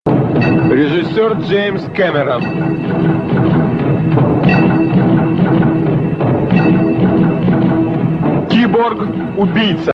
Долгий смех из TikTok